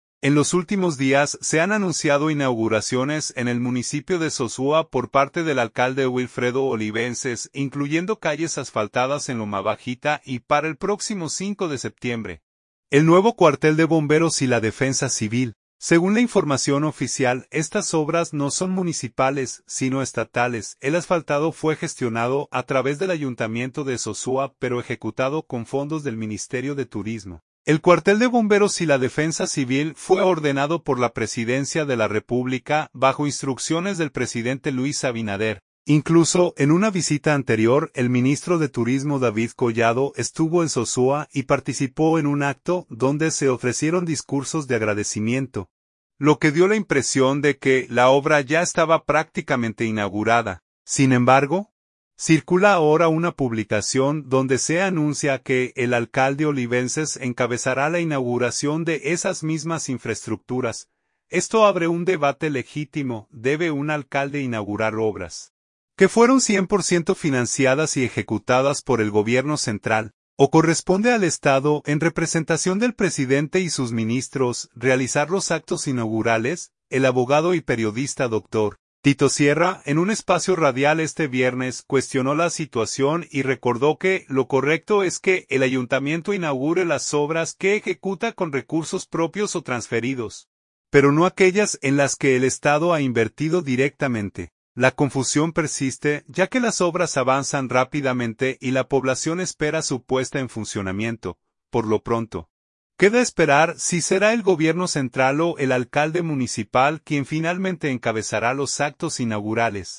en un espacio radial este viernes